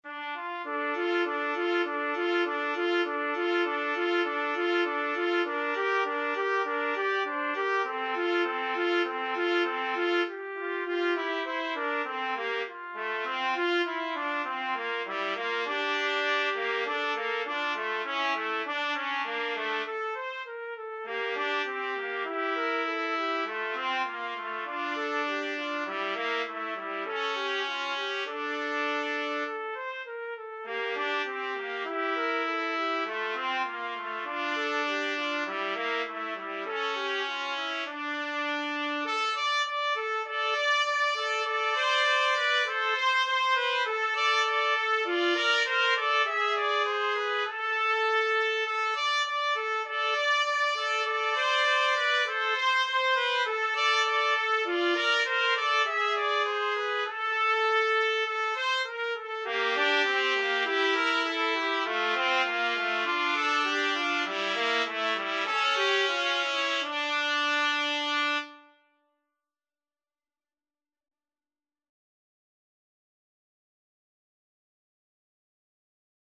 Free Sheet music for Trumpet Duet
D minor (Sounding Pitch) E minor (Trumpet in Bb) (View more D minor Music for Trumpet Duet )
4/4 (View more 4/4 Music)
Moderato
Traditional (View more Traditional Trumpet Duet Music)
world (View more world Trumpet Duet Music)